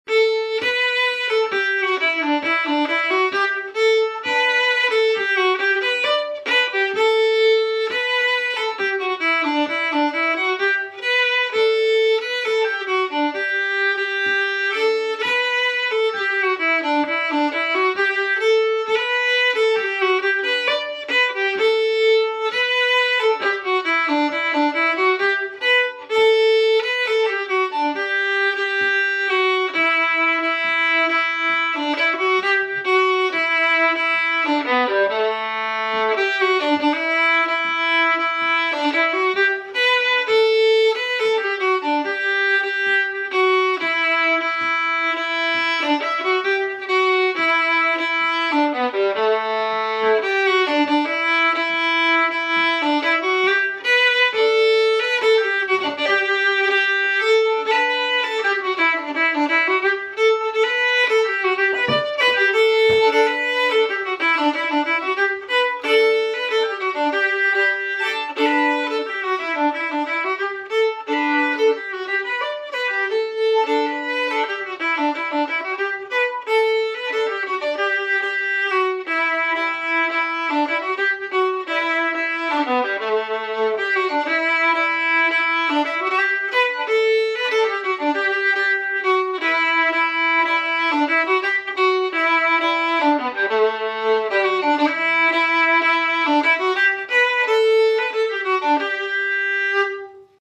Key: G
Form: Reel?
Played slowly for learning, then up to tempo
Region: Québec
This is a nicely syncopated tune in G